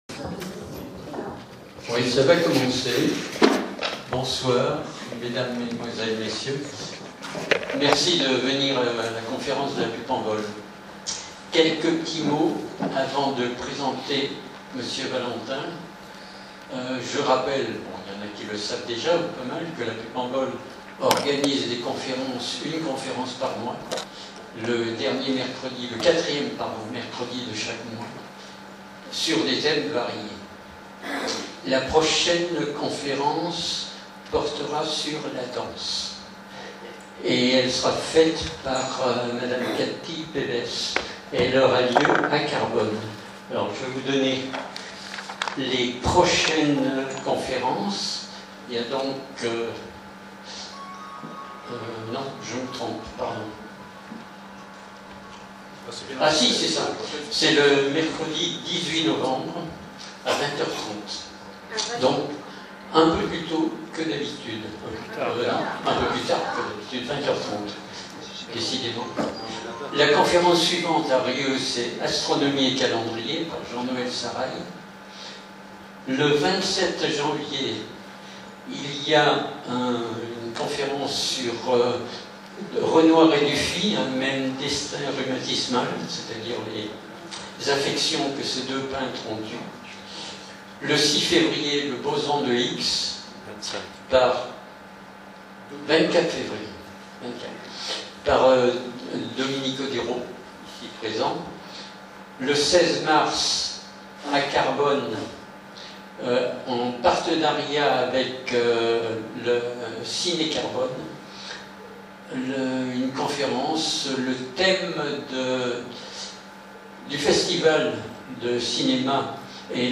Montesquieu-Volvestre - Salle polyvalente Ecouter la conférence